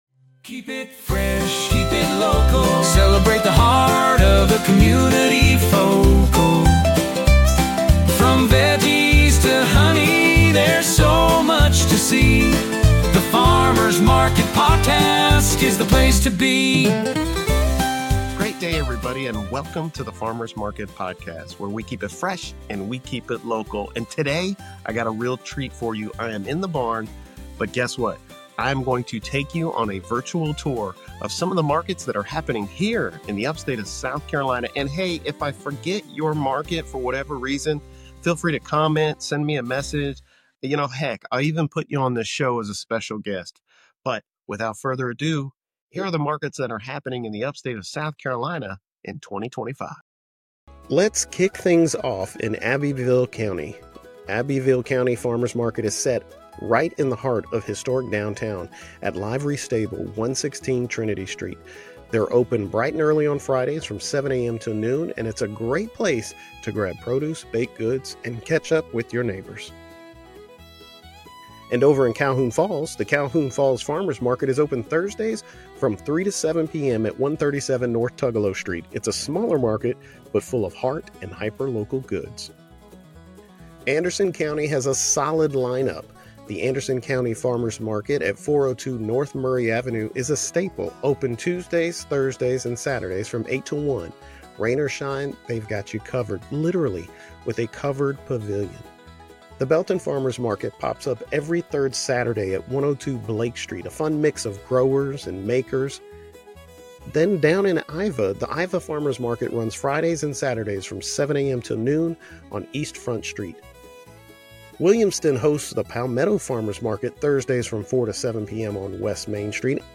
From planting to harvest, you’ll get a behind-the-scenes listen into the daily life of a farmer, their sustainable practices, and how they prepare for opening week.